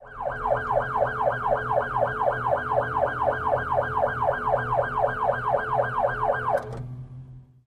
Police Siren: Interior Perspective with Various Types.